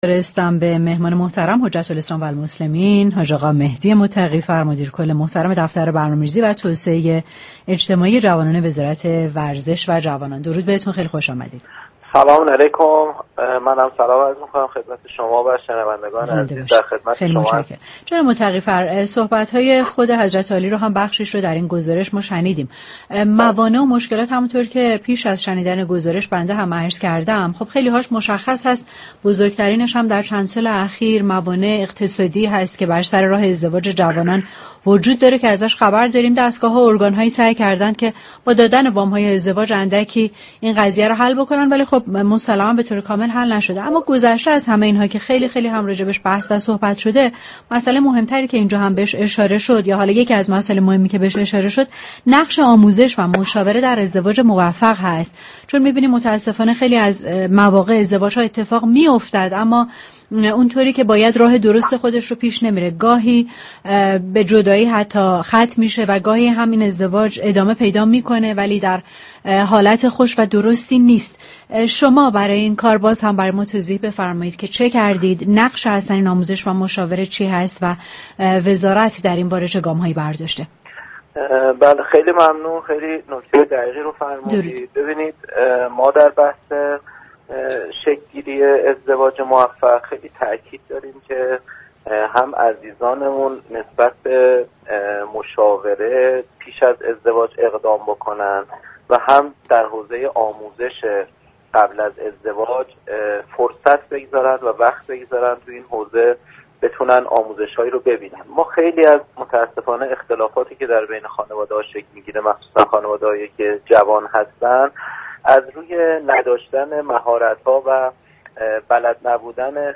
به گزارش پایگاه اطلاع رسانی رادیو تهران، حجت الاسلام و المسلمین مهدی متقی فر مدیركل دفتر كل دفتر برنامه ریزی و توسعه اجتماعی جوانان وزارت ورزش و جوانان و دبیر قرارگاه حمایت از خانواده و جوانی جمعیت در گفت‌وگو با تهران من رادیو تهران درخصوص اقدامات این وزارتخانه در راستای رفع موانع ازدواج گفت: پیشنهاد می‌كنم جوانان قبل از ازدواج از مشاوران خانواده كمك بگیرند زیرا اصل مشاوره برای قبل از اقدام به ازدواج است نه پس از آن.